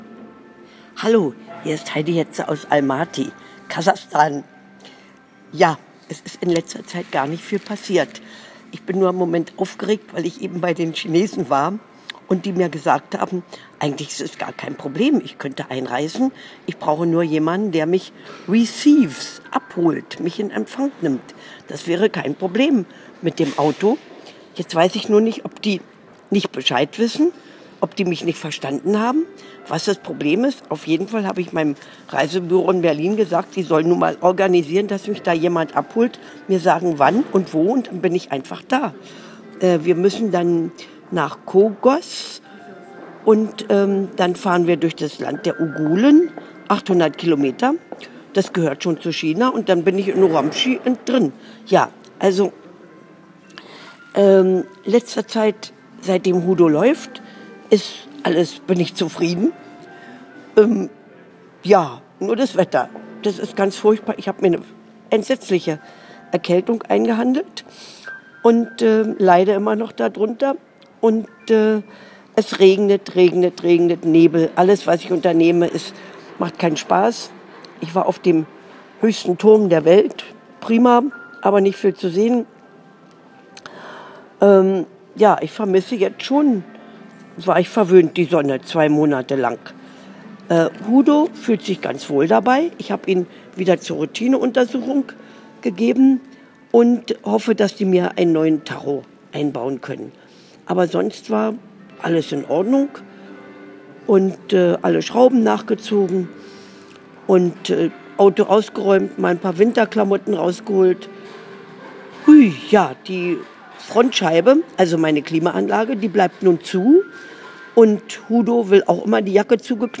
Audiobericht aus Almaty